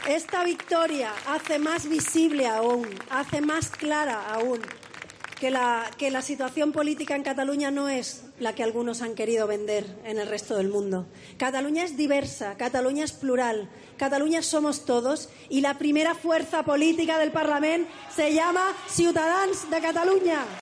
Junto al presidente del partido, Albert Rivera, y arropada por centenares de militantes y simpatizantes en la Avenida María Cristina de Barcelona, donde la formación naranja ha celebrado su victoria en las catalanas, Arrimadas ha resaltado que hoy "ha quedado más claro que la mayoría social de los catalanes está a favor de la unión", por lo que los independentistas "nunca más podrán hablar en nombre de Cataluña".
"Hoy hemos apostado por la unión de todos los catalanes, hemos votado para unir, a favor de la convivencia, del sentido común y de una Cataluña para todos", ha dicho Arrimadas, aclamada por los simpatizantes al grito de "presidenta, presidenta", y que han ondeado las banderas catalana y española.